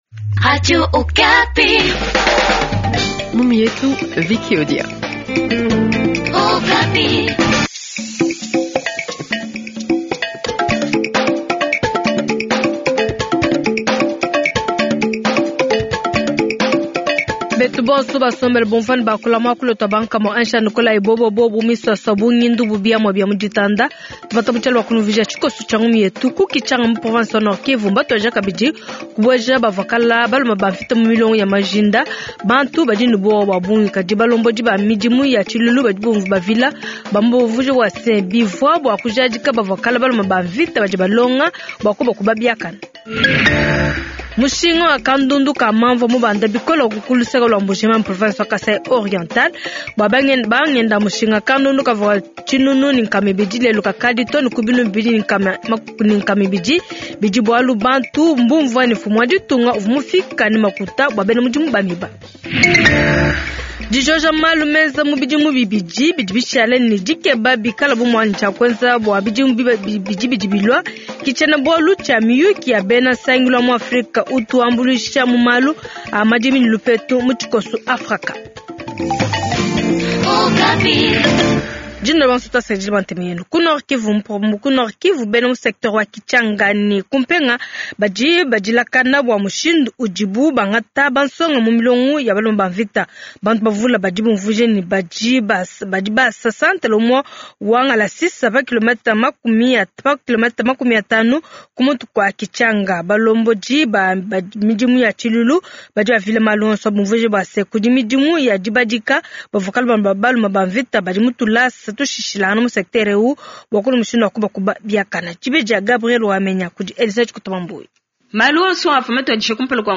Journal Tshiluba Soir